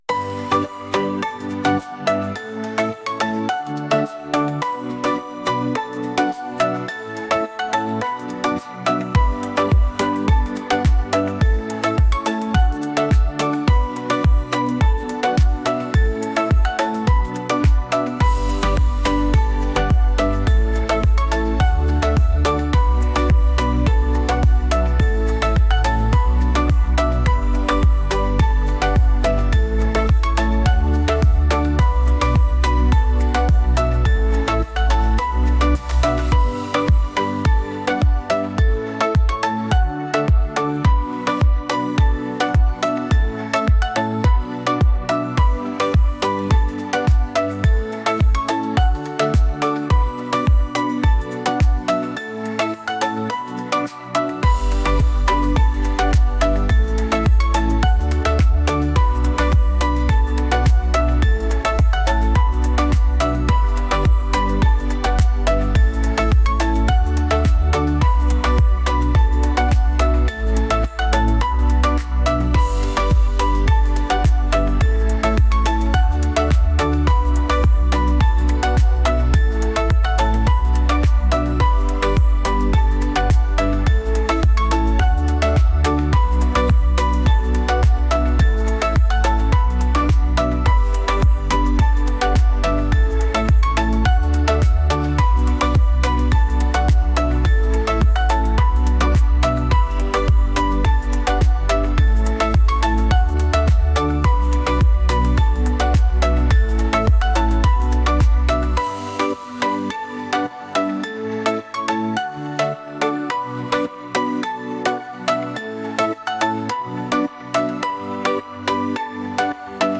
pop | catchy